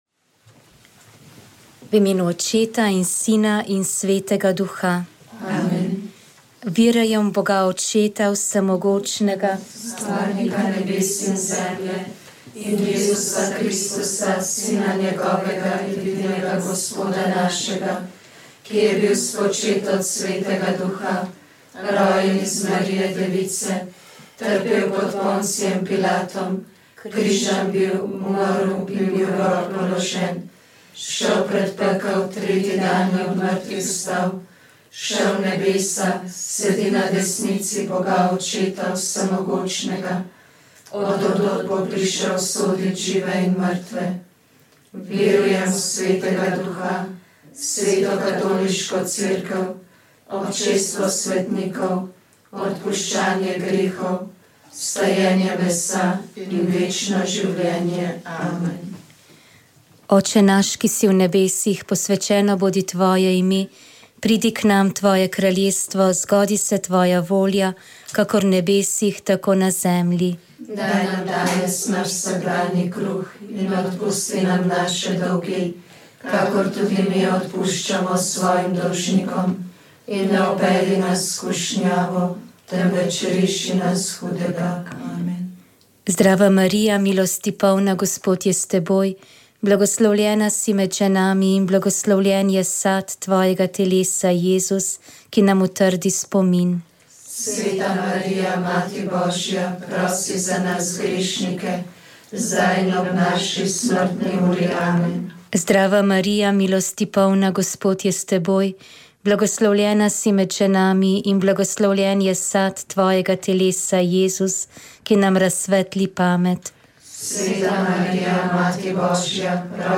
Gre za prvi intervju s predsednico države na našem radiu po nastopu mandata, zato smo osvetlili nekaj njenih načrtov in slišali stališča do domačega in tujega dogajanja.